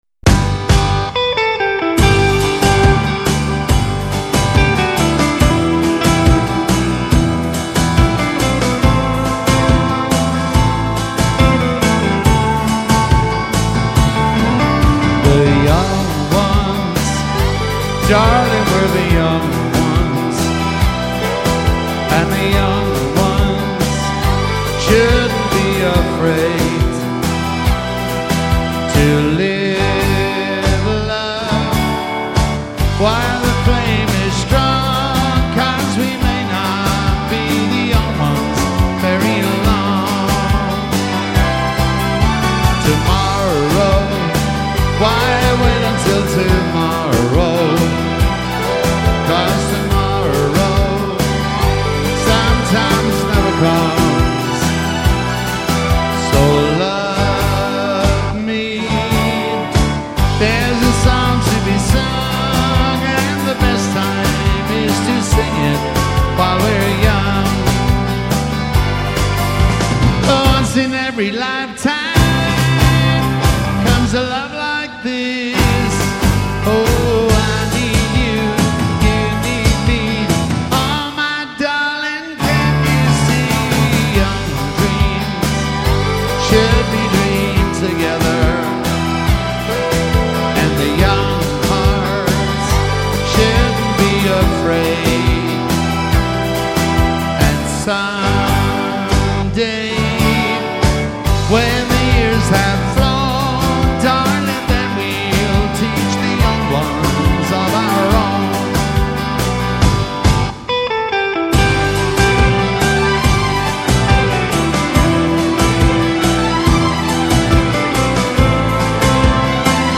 Irish country singer